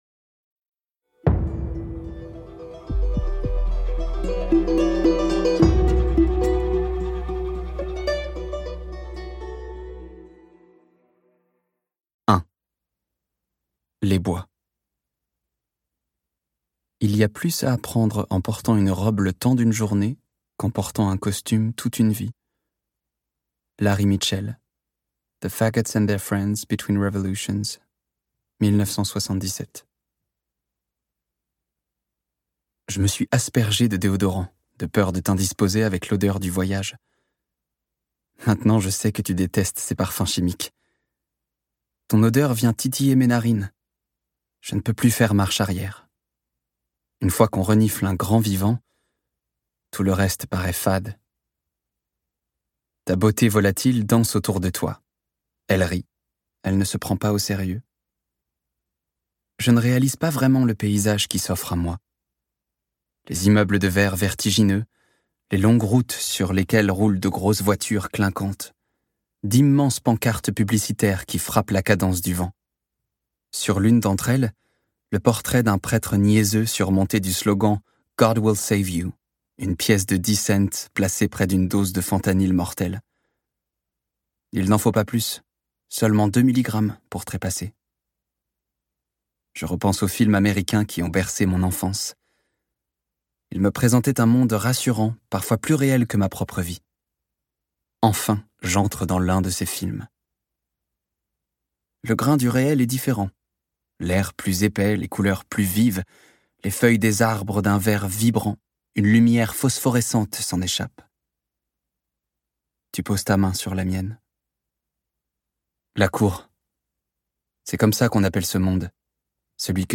Libérateur et inspirant.Ce livre audio est interprété par une voix humaine, dans le respect des engagements d'Hardigan.